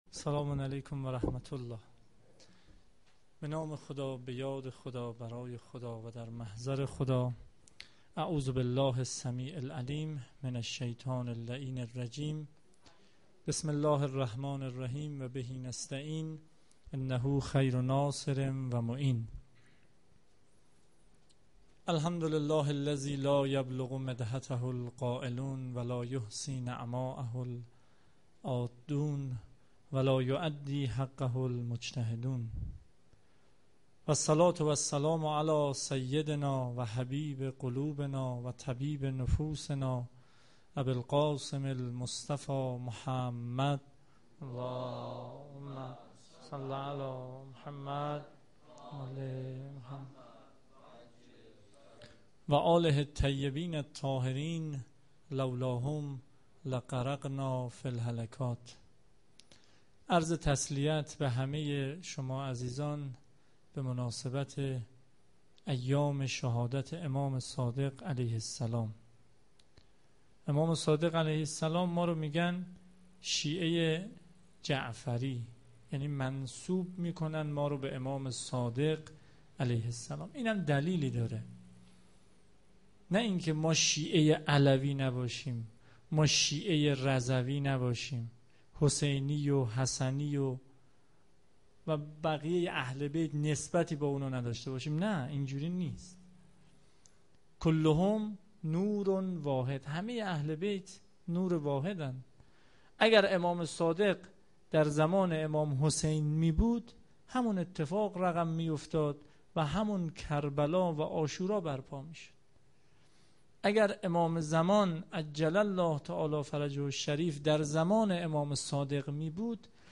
سخنرانی
ویژه برنامه شهادت امام صادق